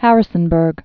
(hărĭ-sən-bûrg)